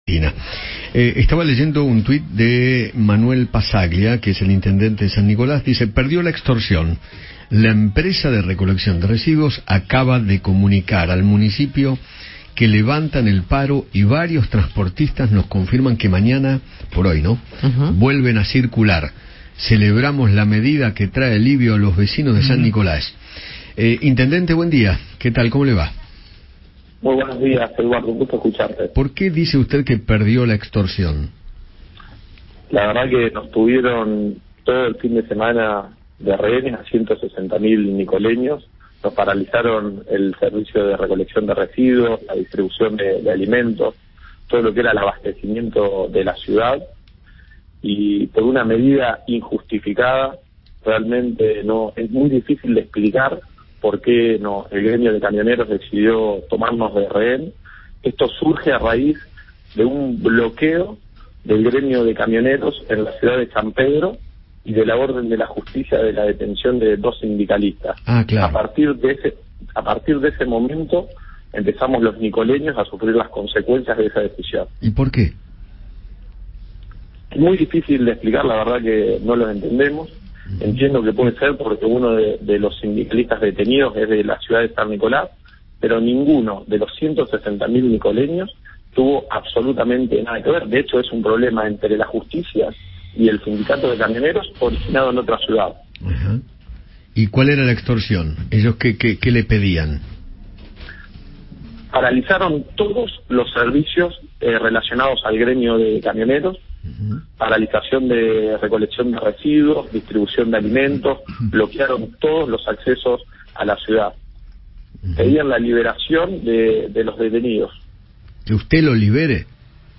Manuel Passaglia, intendente de San Nicolás, dialogó con Eduardo Feinmann sobre el paro que realizó el gremio de Camioneros en aquella ciudad y contó que “bloquearon todos los accesos”.